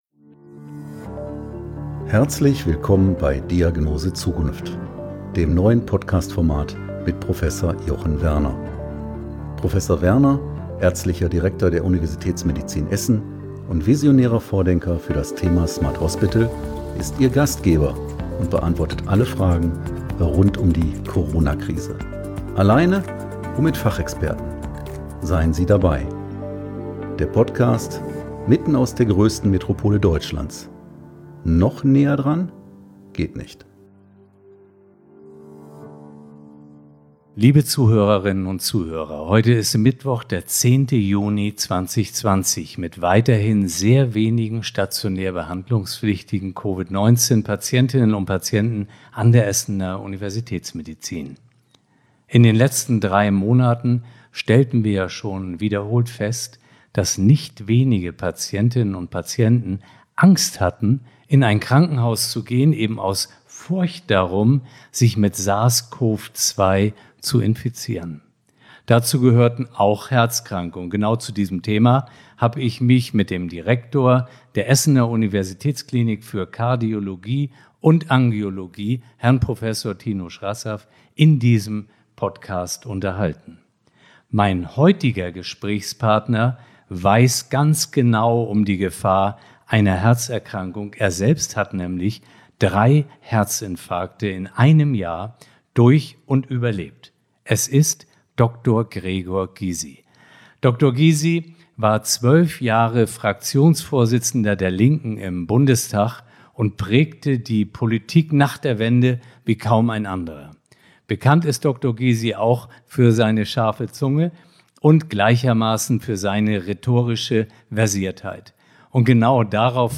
Heute bin ich im Gespräch mit Gregor Gysi – Rechtsanwalt, Politiker, Autor und Moderator. Was genau verbirgt sich dahinter, wenn sich Herr Gysi als Zweckoptimist bezeichnet?